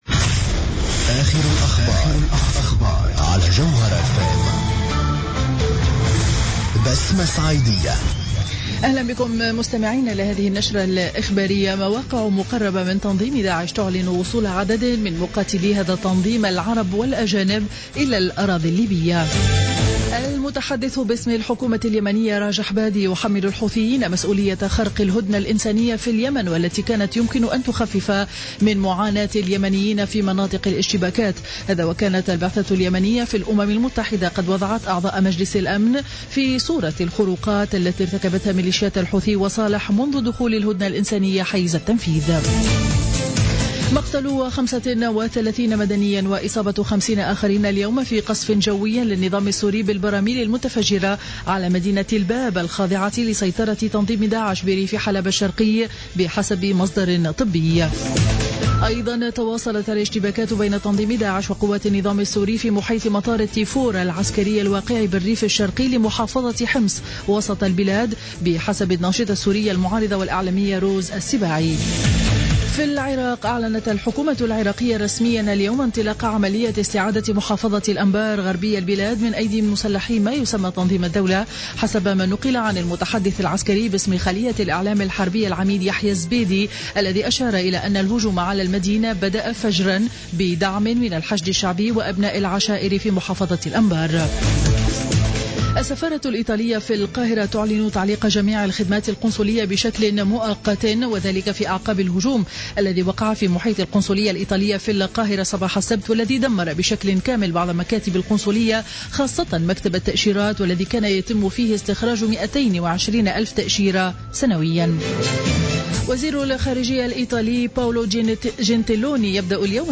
نشرة أخبار منتصف النهار ليوم الاثنين 13 جويلية 2015